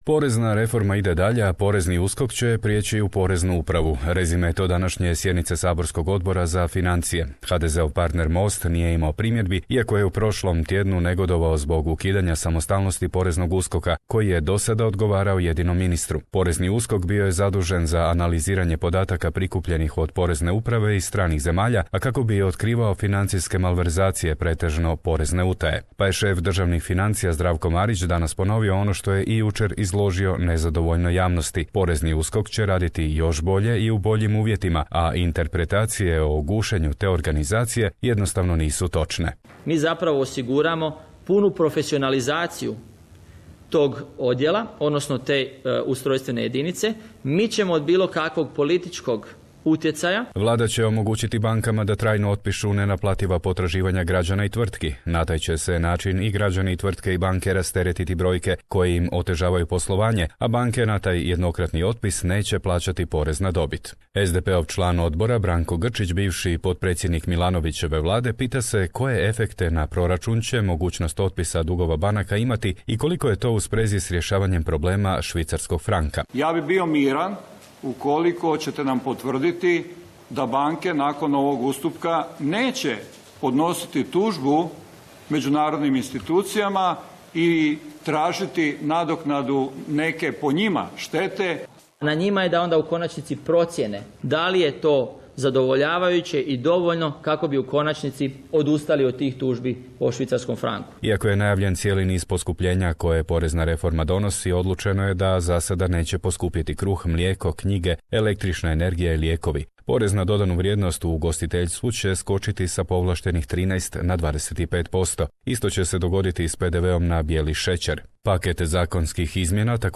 Round-up of daily news and current affairs from Croatia.